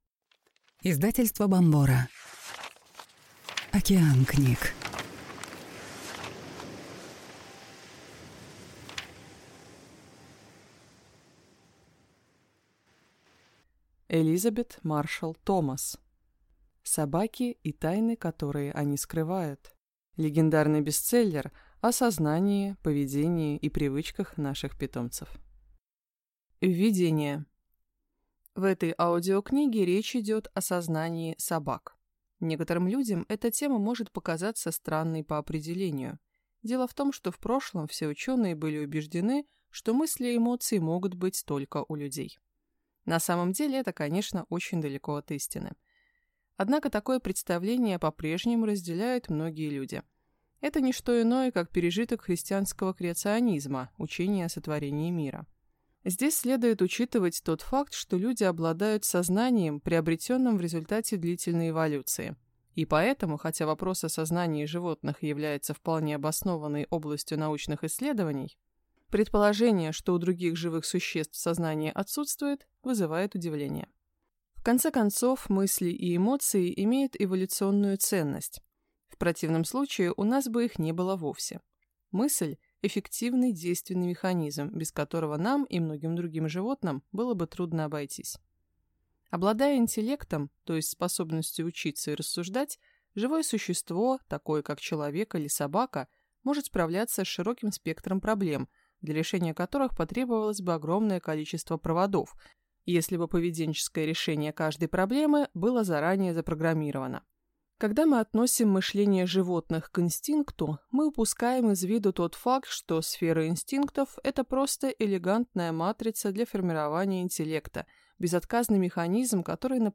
Аудиокнига Собаки и тайны, которые они скрывают. Легендарный бестселлер о сознании, поведении и привычках наших питомцев | Библиотека аудиокниг